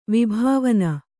♪ vibhāvana